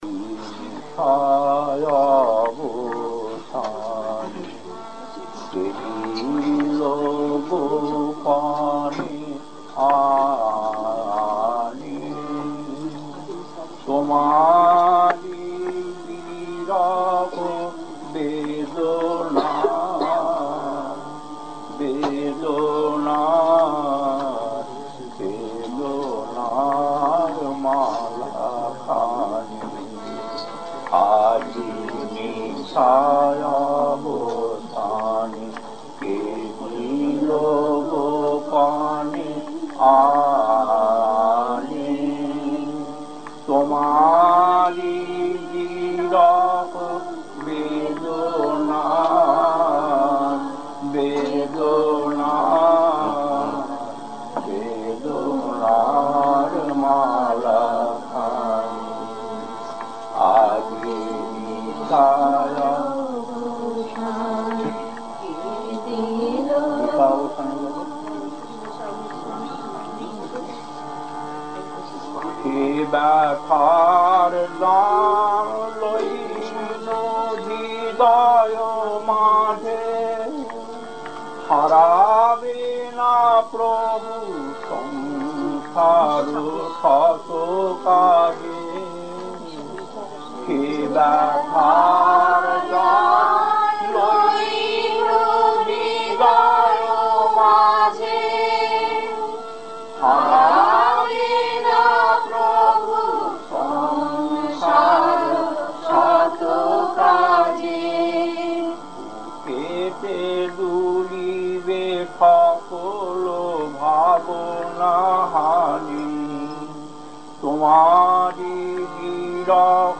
Kirtan B11-2 Harbour Heights, 49 Minutes 1.